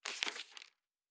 Fantasy Interface Sounds